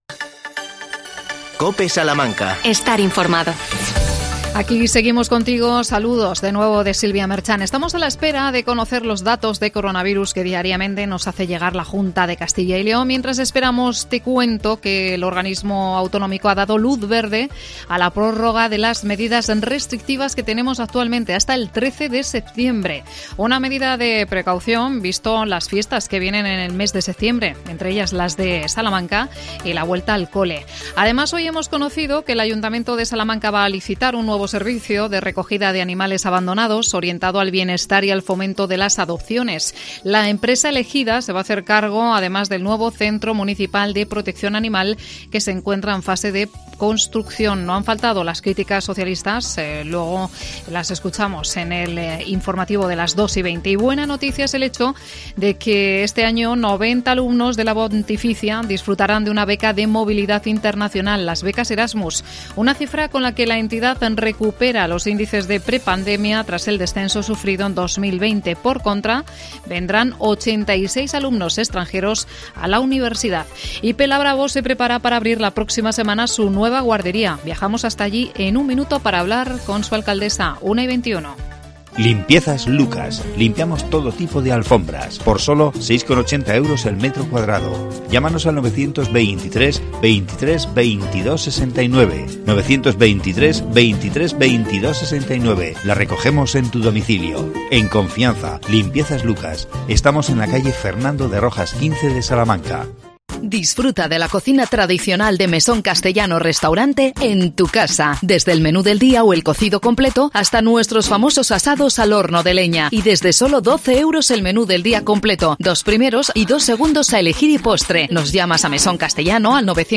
AUDIO: Pelabravo estrena el 1 de septiembre su guardería municipal Entrevistamos a su alcaldesa Maite García.